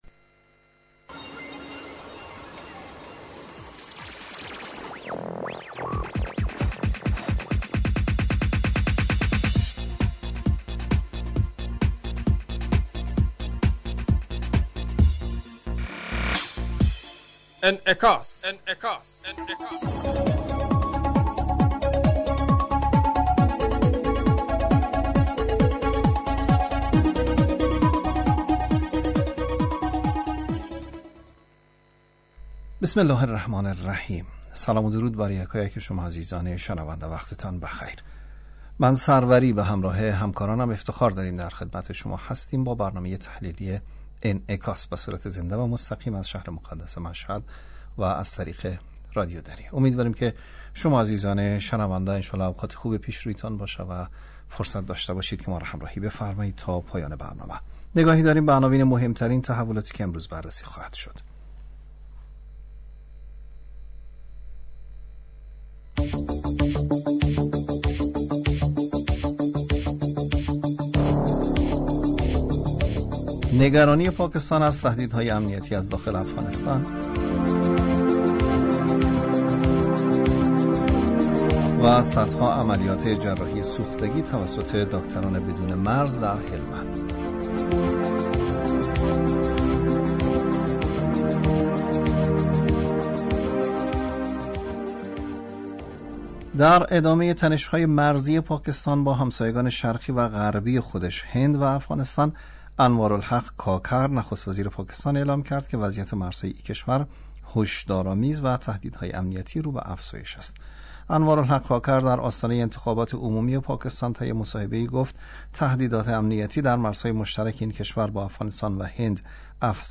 جشن «شکوفه‌ها» امروز ۲۹ شهریور/ سنبله 1402 در دبستان دخترانه زهرا مردانی شهر تهران با حضور وزیر آموزش و پرورش همزمان در سراسر ایران برگزار شد.